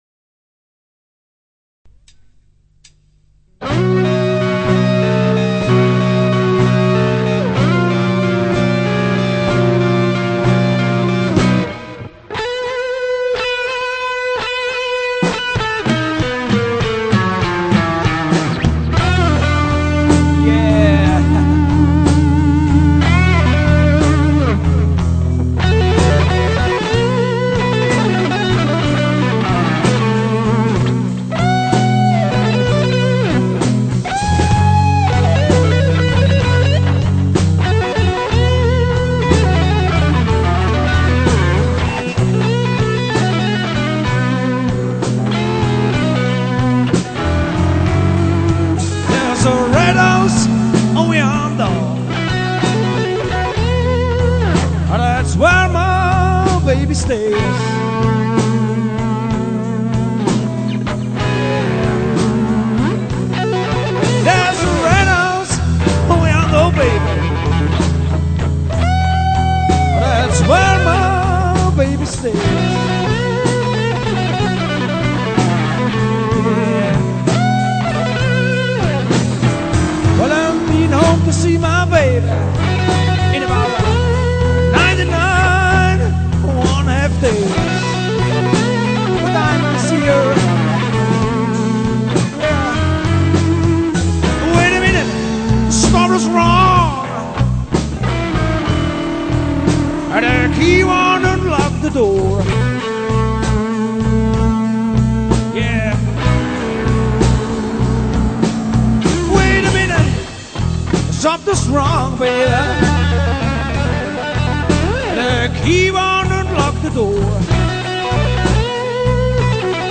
'verewigten' wir einen Teil des damaligen Cover-Repertoires.